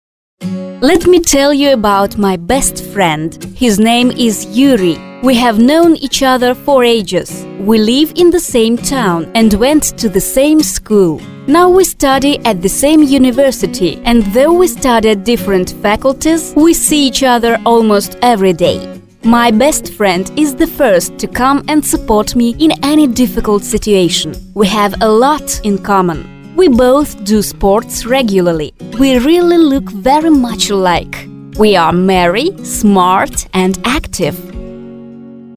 Жен, Закадровый текст/Средний
Микрофон Октава МК-219 Звуковая карта Focusrite Scarlett 2i2 Кабинка